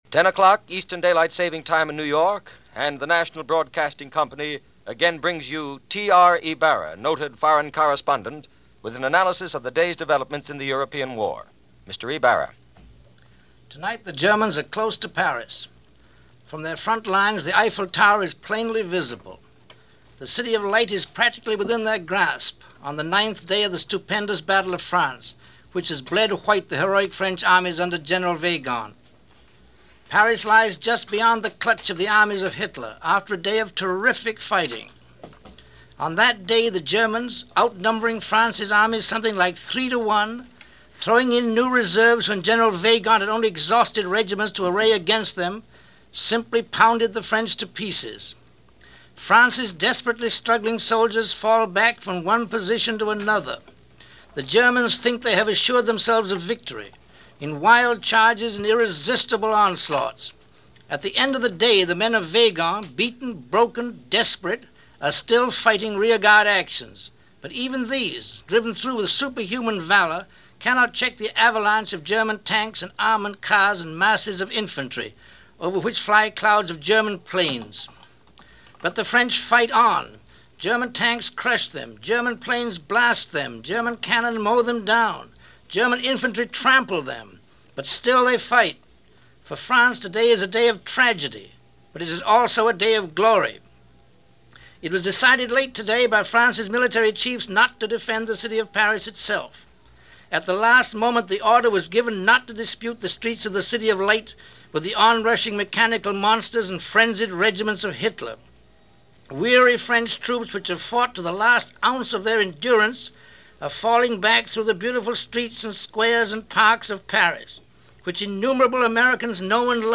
Fall Of France - Opportunity Evaporating - June 13, 1940 - news reports and analysis regarding the state of France under German invasion.